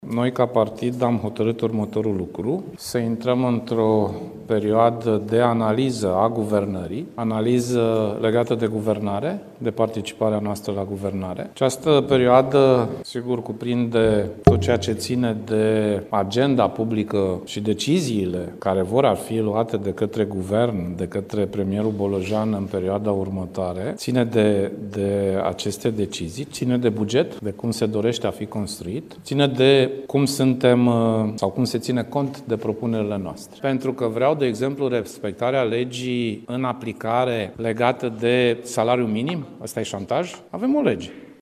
Președintele PSD, Sorin Grindeanu, după ședința Biroului Permanent Național: „Noi, ca partid, am hotărât următorul lucru: să intrăm într-o perioadă de analiză a guvernării, legată de guvernare, de participarea noastră la guvernare”